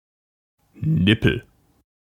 Ääntäminen
IPA: /ɡrɛj/